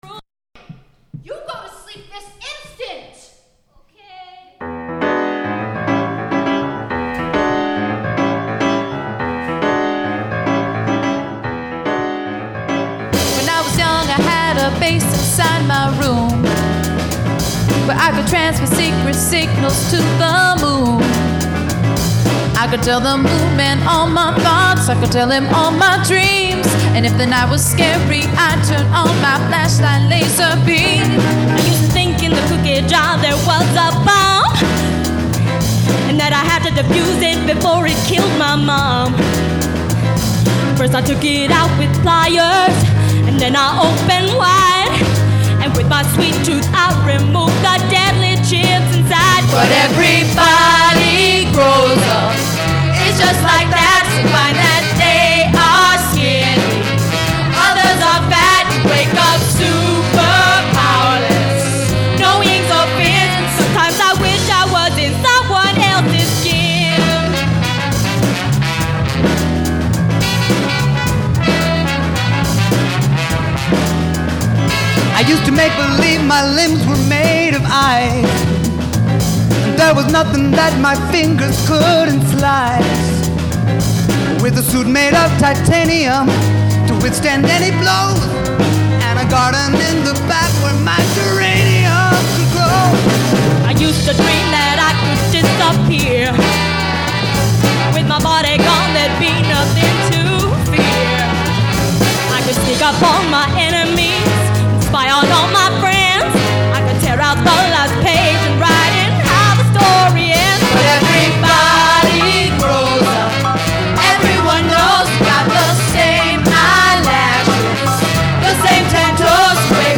Live Recording 10/2/98